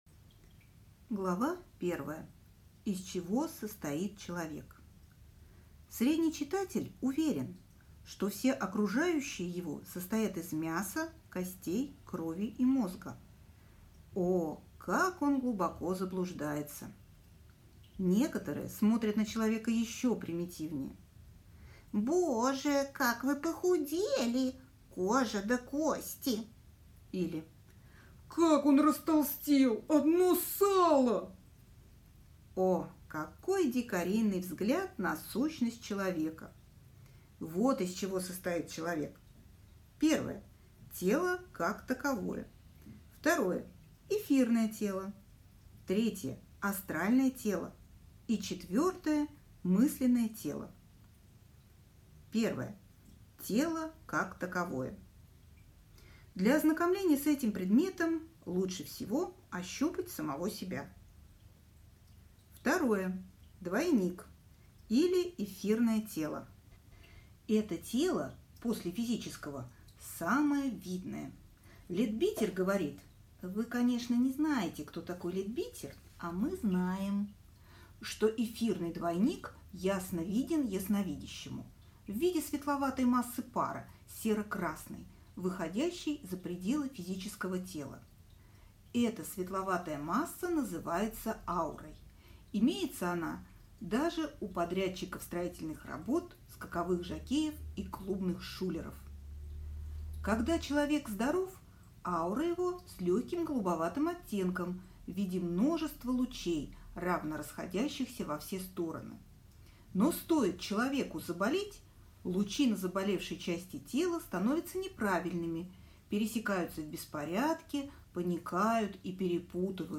Аудиокнига Оккультные науки | Библиотека аудиокниг